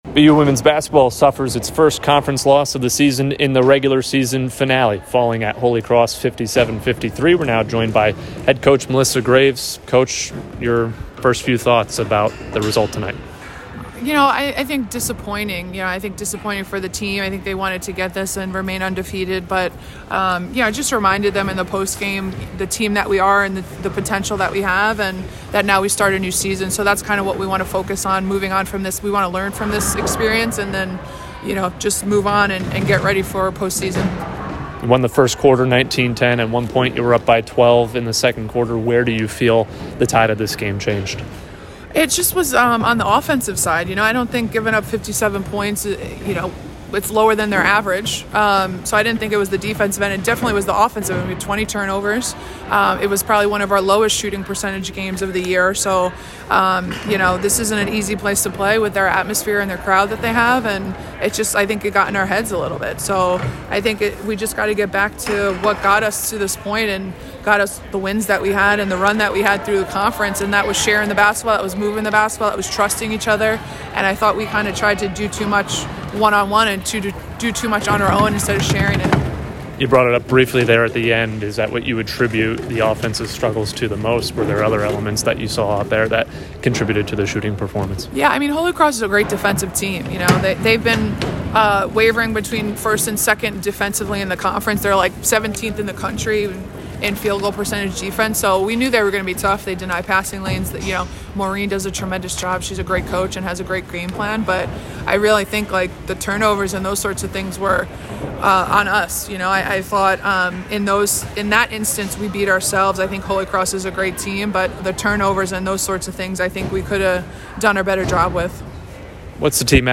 Holy Cross Postgame Interview
WBB_Holy_Cross_2_Postgame.mp3